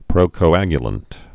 (prōkō-ăgyə-lənt)